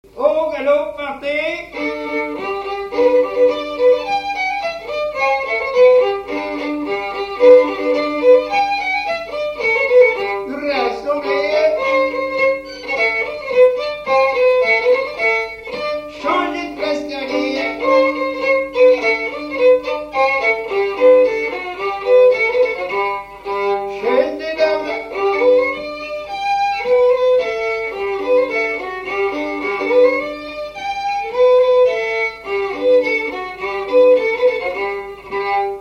instrumental
danse : quadrille : galop
Pièce musicale inédite